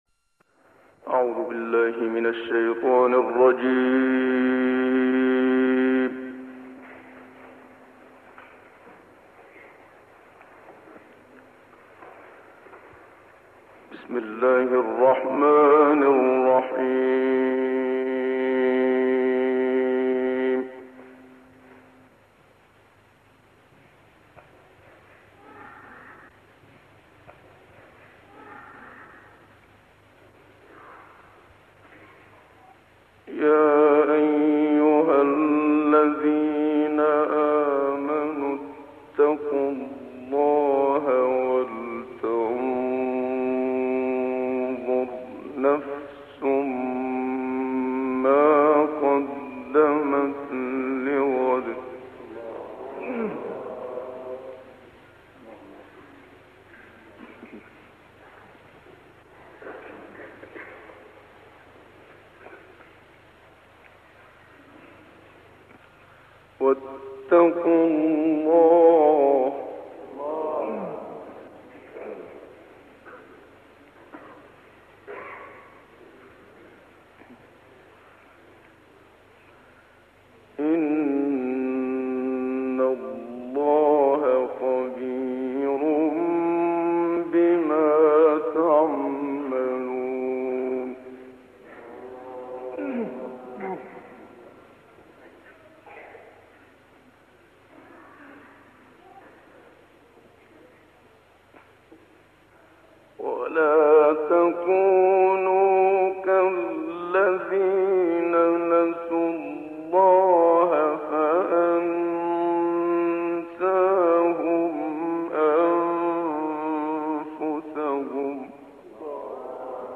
سوره حشر با تلاوت استاد منشاوی + دانلود/ وعده پیروزی به حزب‌الله
گروه فعالیت‌های قرآنی: تلاوتی زیبا از استاد محمد صدیق منشاوی از آیات ۱۸-۲۴ سوره حشر و سوره‌های طارق، فجر، حمد و آیات ۱-۵ سوره بقره ارائه می‌شود.